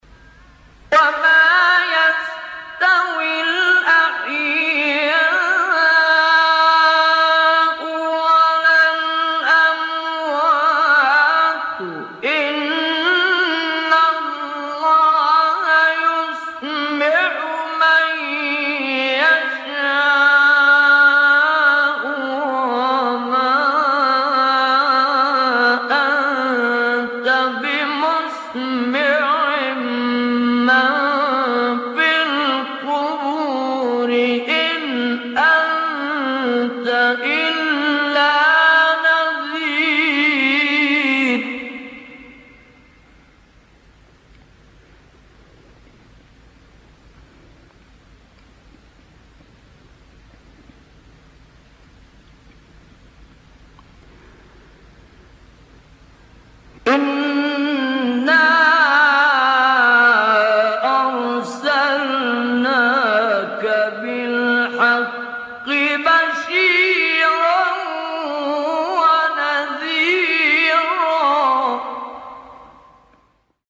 گروه شبکه اجتماعی: نغمات صوتی با صدای قاریان برجسته مصری ارائه می‌شود.
مقطعی در مقام راست رهاوی با صوت حمدی الزامل